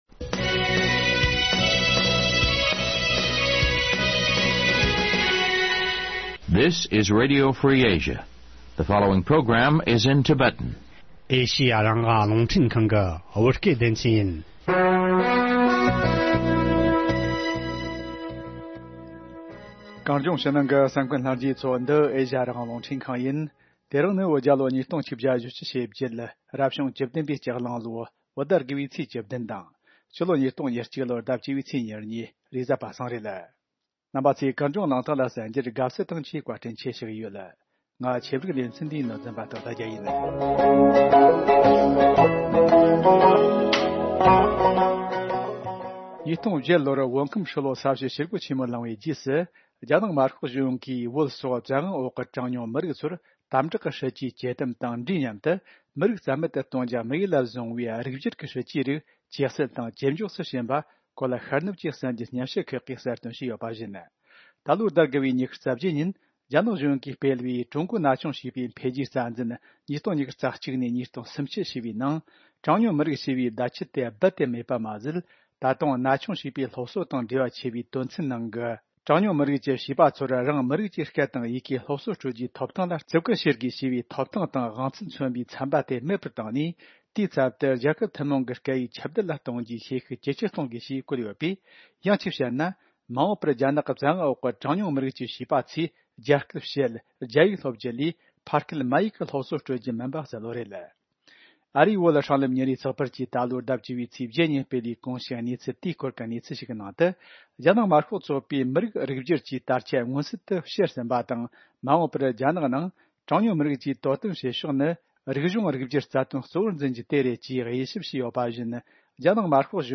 གླེང་མོལ་ཞུས་པའི་ལས་རིམ།